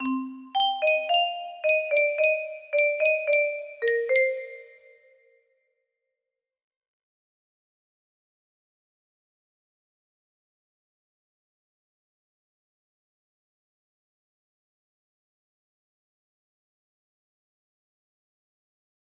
o music box melody